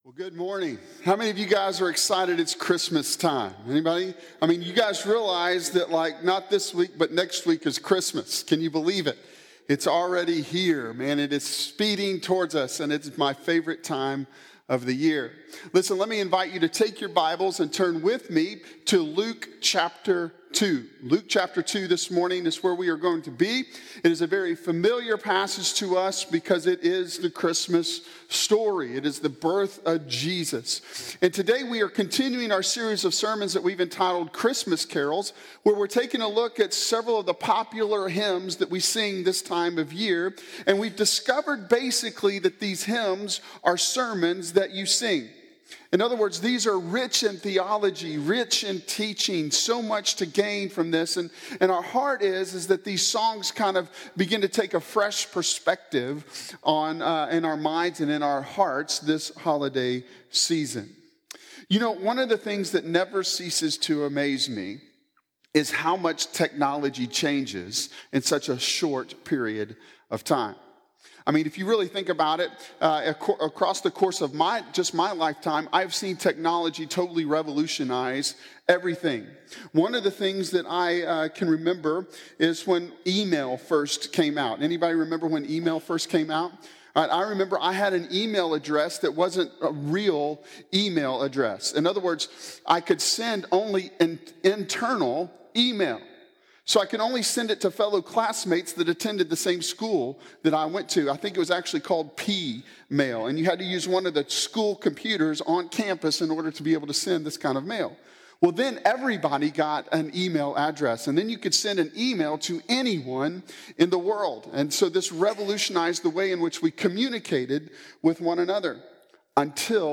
Christmas Carols Sermon Series